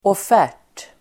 Uttal: [åf'är_t:]